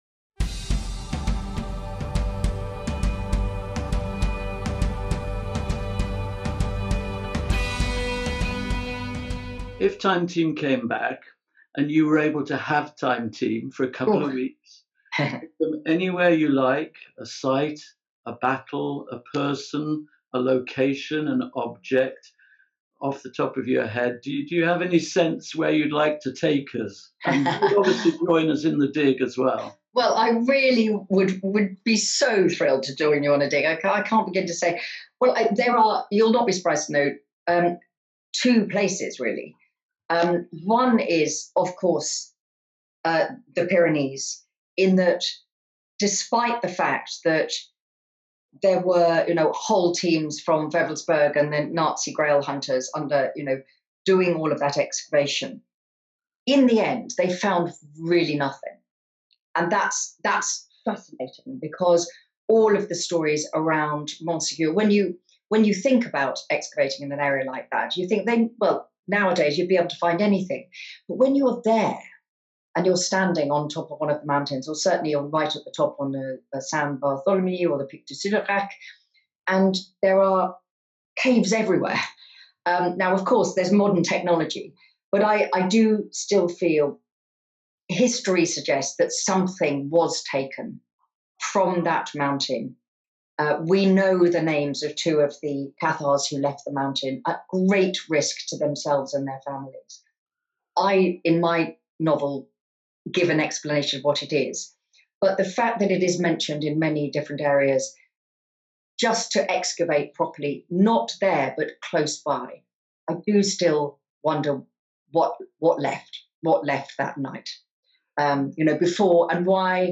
In the second part of our revealing interview with Kate Mosse, the bestselling author of Labyrinth, Citadel and Sepulchre discusses her fantasy digs sites, upcoming novel, The City of Tears, and why it's essential that Time Team returns.